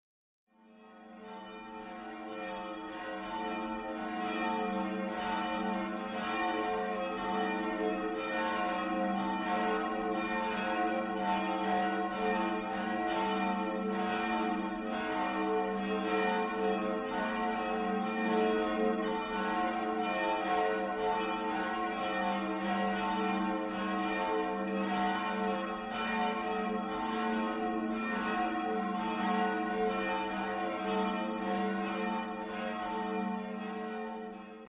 Im Jahre 1994 kam eine weitere Glocke hinzu, so dass heute ein wunderschönes Vierergeläute zu Gebet und Gottesdienst ruft.
Hörbeispiel der Großdrebnitzer Glocken
Die-Glocken-der-Martinskirche-Grossdrebnitz.mp3